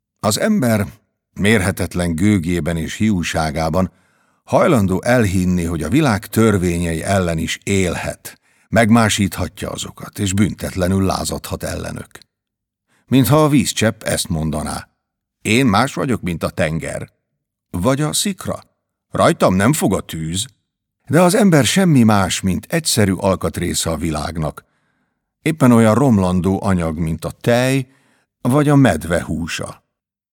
Album: Hangos könyvek felnőtteknek